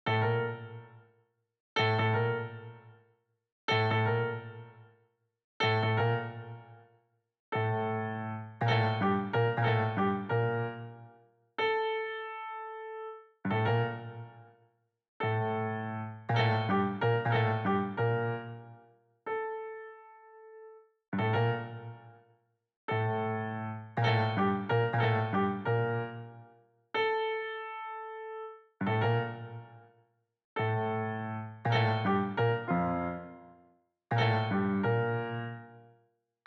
piano 01.wav